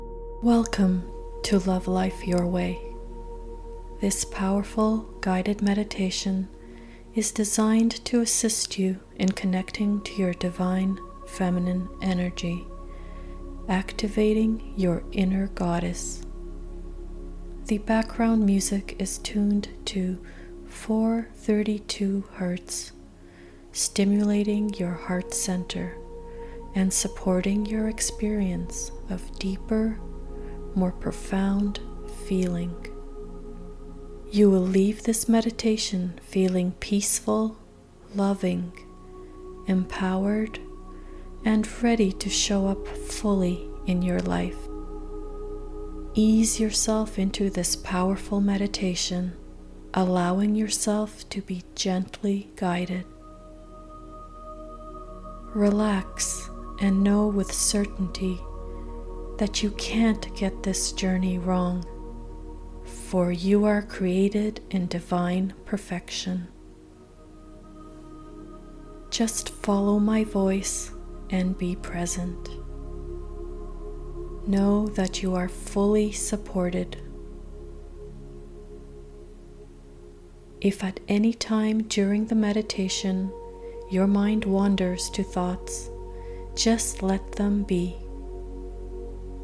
Sample - Awaken Divine Feminine Energy Guided Meditation
The relaxing background music is tuned to 432 Hz, stimulating your heart center, and supporting your experience of deeper, more profound feeling.